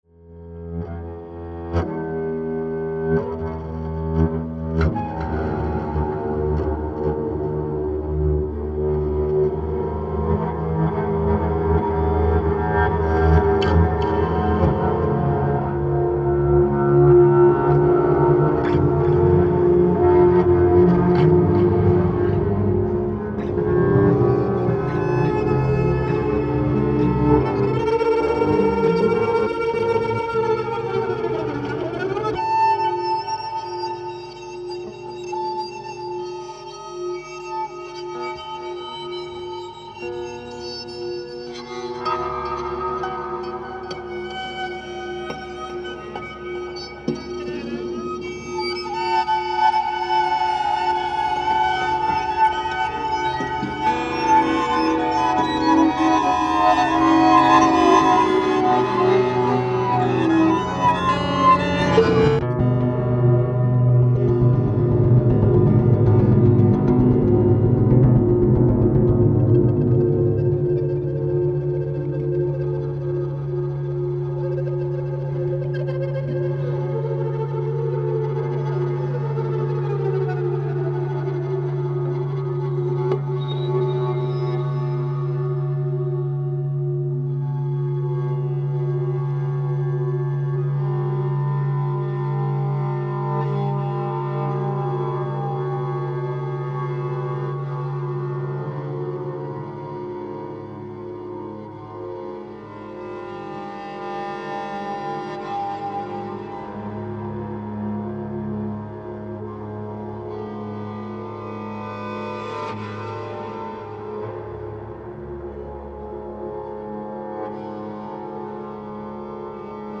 laptop, live electronics, real-time sampling, Max-Msp